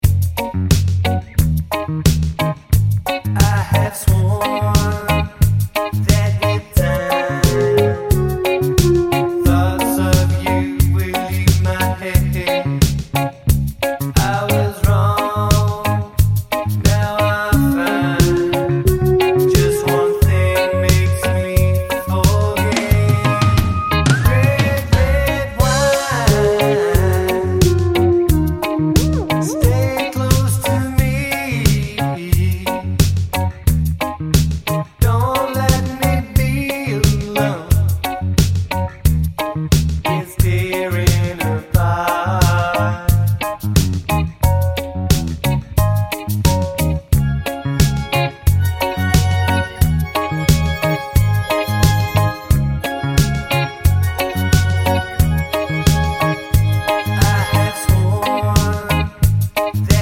Album Version Reggae 5:30 Buy £1.50